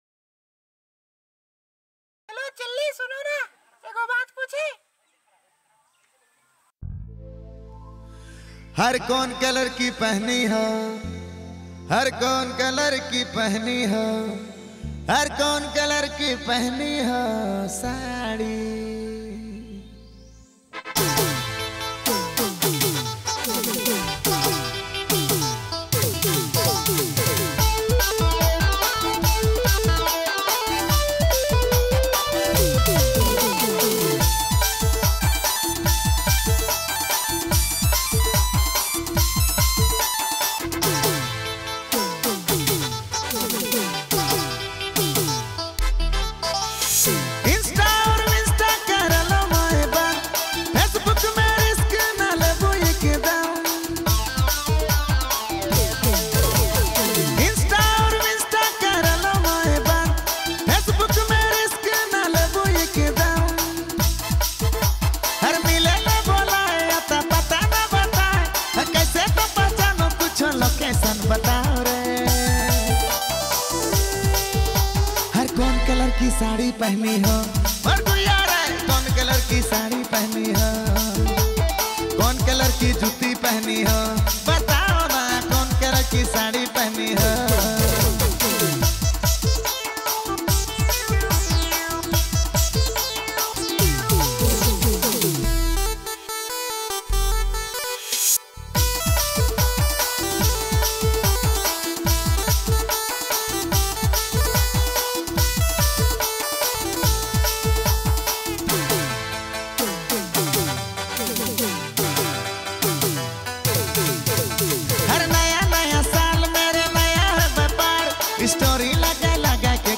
Releted Files Of Nagpuri Gana